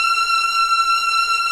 Index of /90_sSampleCDs/Roland - String Master Series/CMB_Combos 2/CMB_Hi Strings 3
STR SLOW V0C.wav